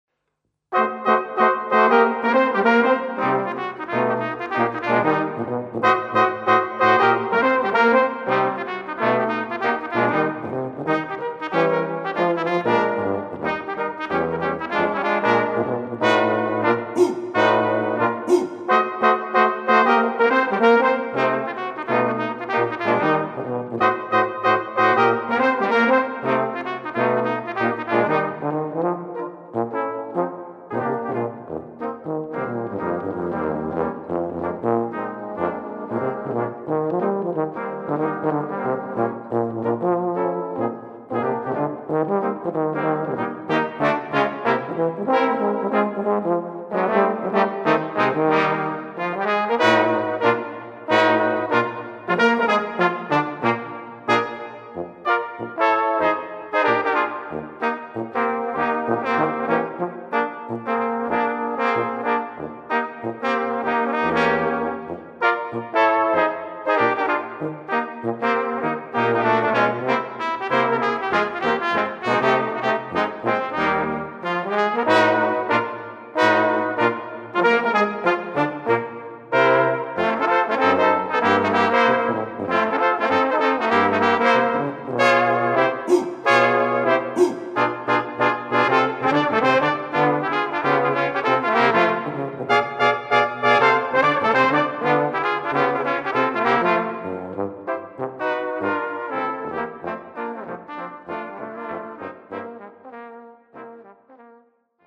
Gattung: für 2 Trompeten und 2 Posaunen
Besetzung: Ensemblemusik für 4 Blechbläser